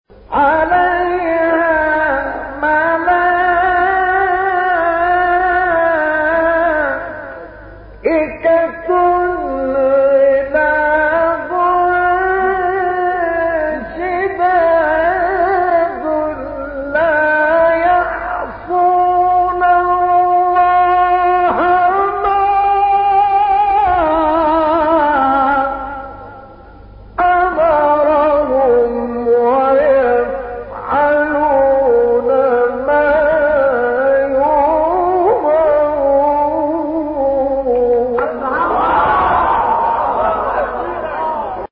گروه شبکه اجتماعی: مقاطعی صوتی از تلاوت قاریان برجسته مصری را می‌شنوید.
مقطعی از مصطفی اسماعیل/ سوره تحریم در مقام صبا و چهارگاه